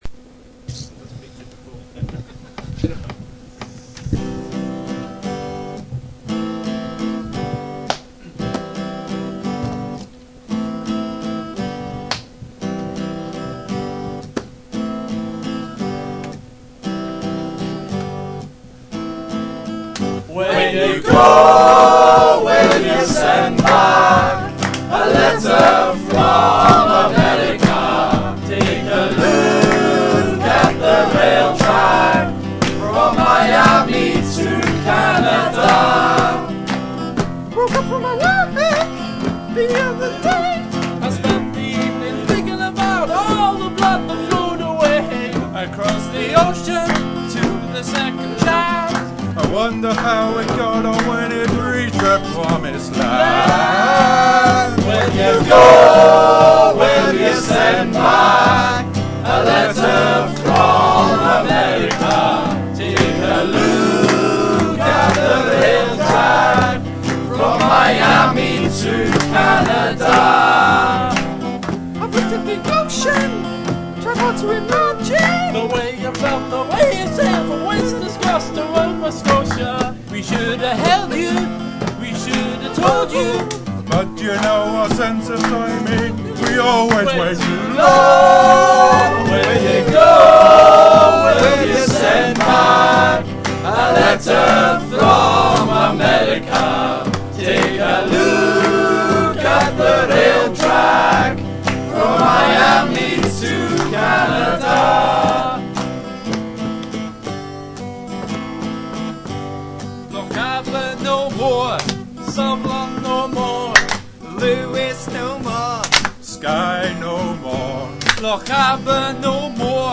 Mixture of veterans and first-timers
booming baritone